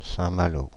Saint-Malo (UK: /sæ̃ ˈmɑːl/,[3] US: /ˌsæ̃ məˈl/,[4][5] French: [sɛ̃ malo]
Fr-Paris--Saint-Malo.ogg.mp3